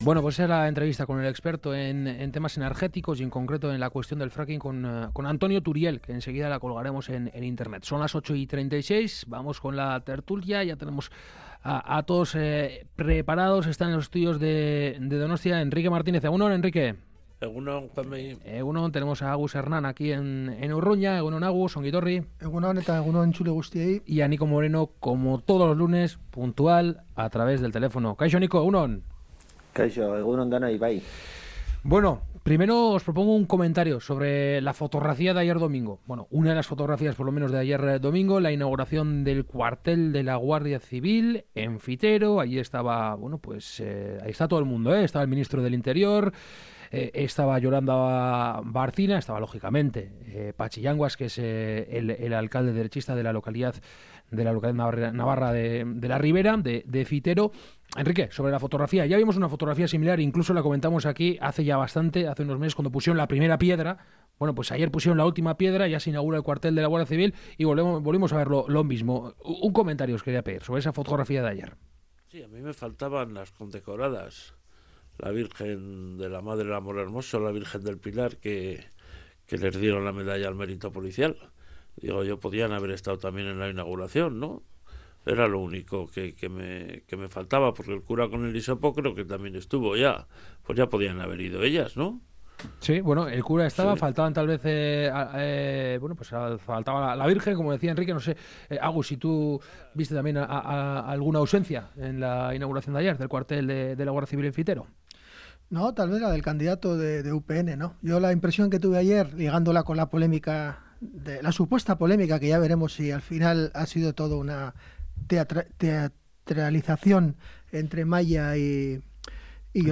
La tertulia de Kalegorrian: inauguración del cuartel Fitero, Brian Currin…
Charlamos y debatimos sobre algunas de las noticias más comentadas de la semana con nuestros colaboradores habituales.